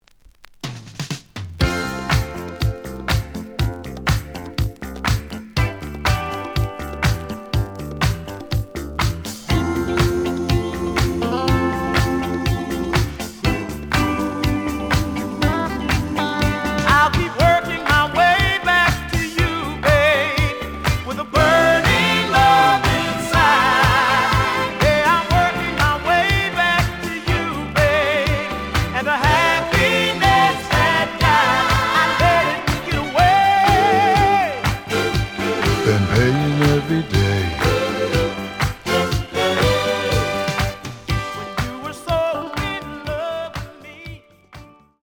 試聴は実際のレコードから録音しています。
●Format: 7 inch
●Genre: Disco